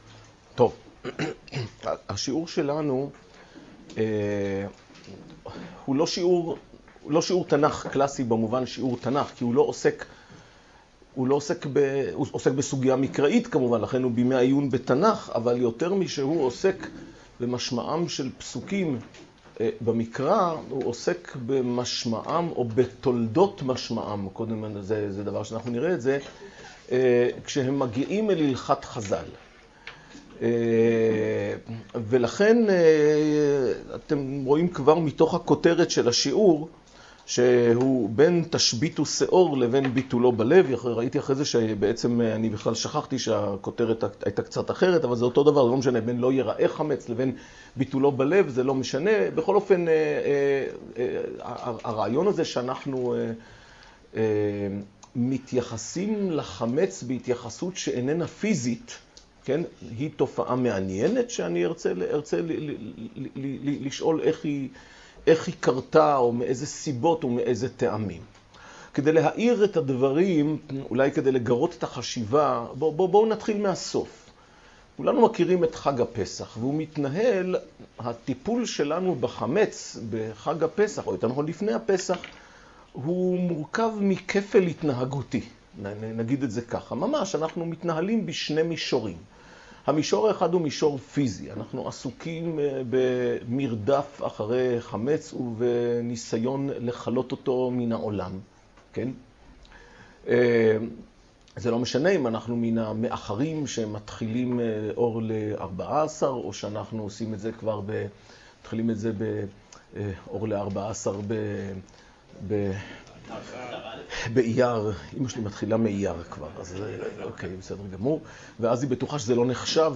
השיעור באדיבות אתר התנ"ך וניתן במסגרת ימי העיון בתנ"ך של המכללה האקדמית הרצוג תשע"ח